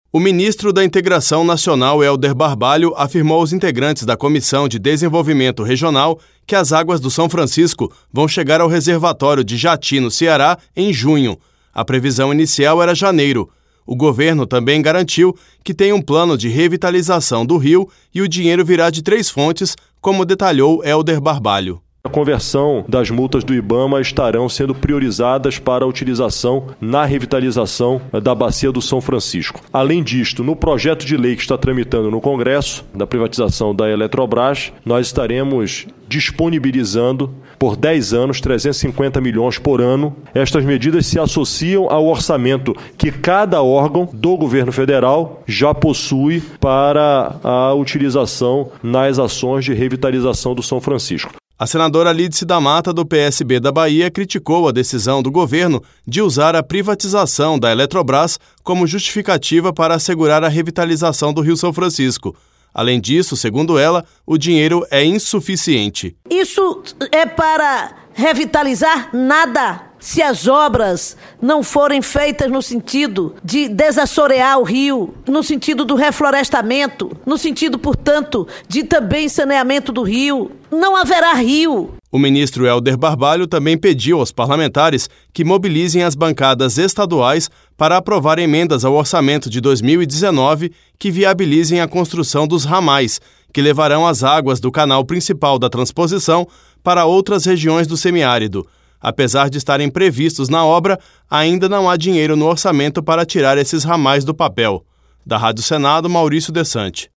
A transposição do rio São Francisco foi tema de audiência pública nesta terça-feira (20) na Comissão de Desenvolvimento Regional e Turismo (CDR). O ministro da Integração Nacional, Helder Barbalho, falou aos senadores sobre o cronograma das obras do Eixo Norte e o dinheiro para a conclusão do projeto.